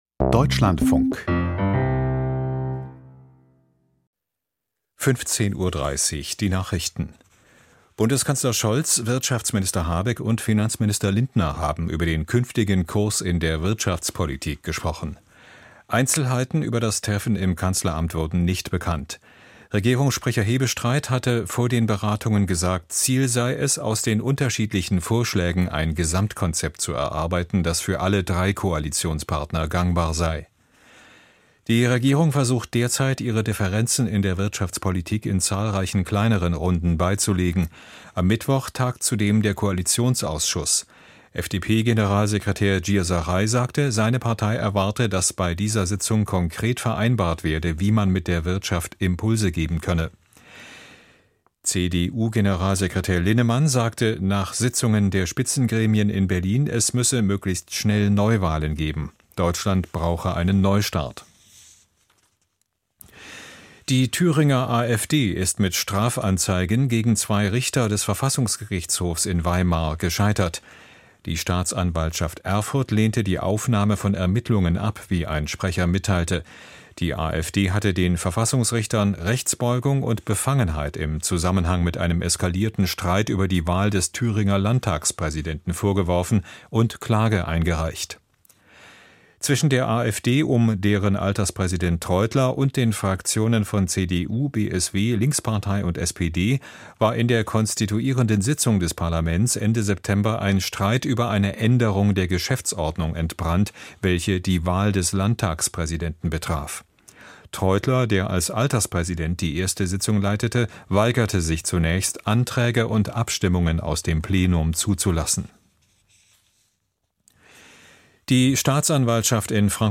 Die Deutschlandfunk-Nachrichten vom 04.11.2024, 15:30 Uhr